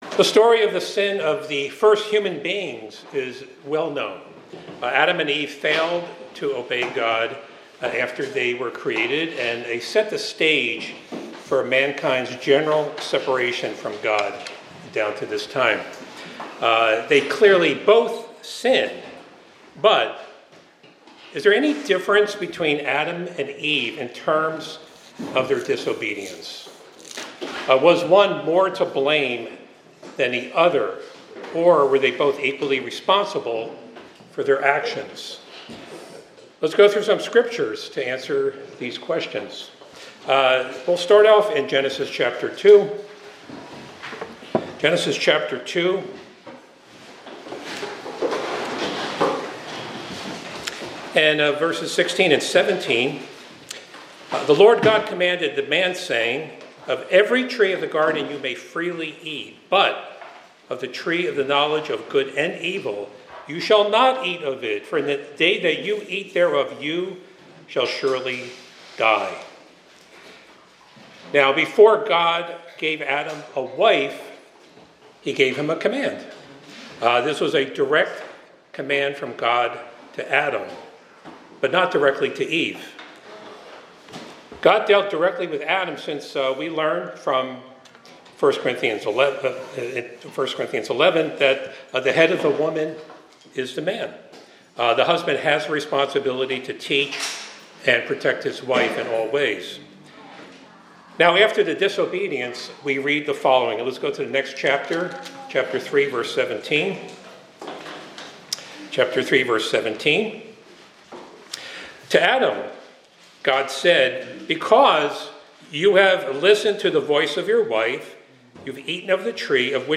This sermonette explores the biblical account of Adam and Eve's sin, emphasizing the differences in their disobedience and the consequences that followed. It highlights the direct command given to Adam, Eve's deception by the serpent, and the theological interpretations of their actions.
Given in Hartford, CT